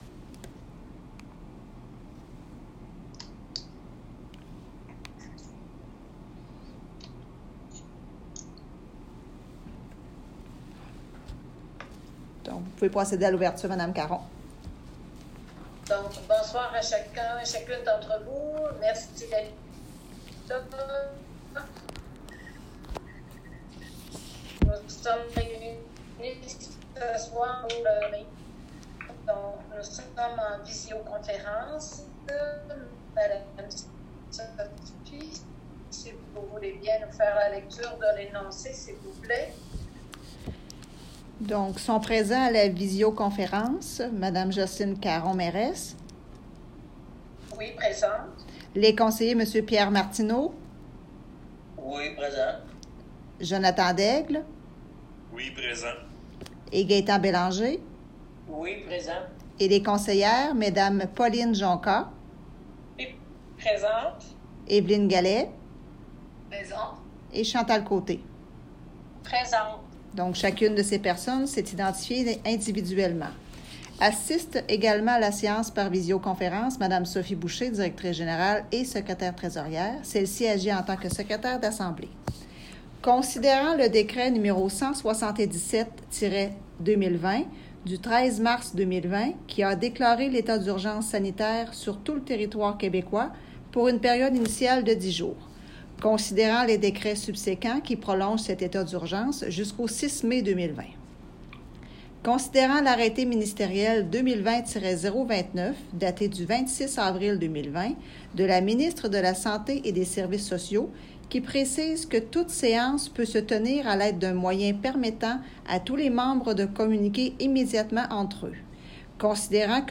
7 février 2022 Séance ordinaire